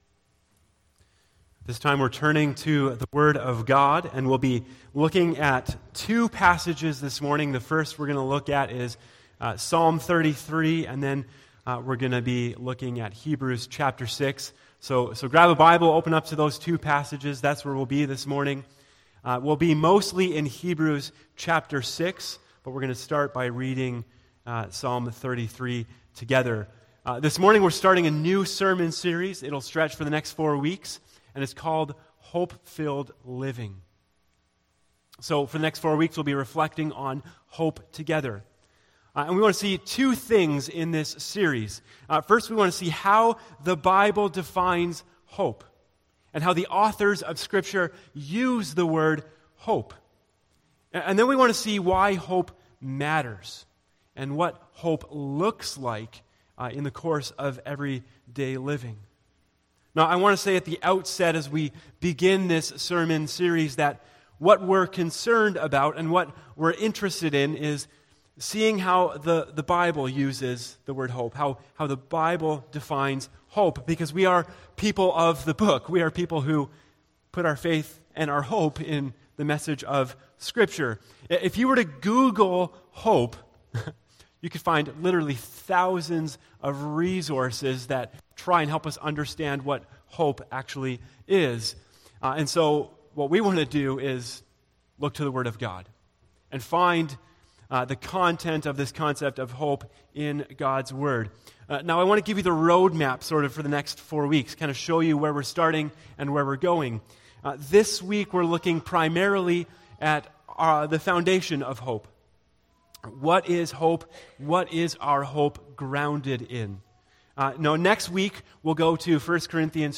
A message from the series "Upside Down Kingdom."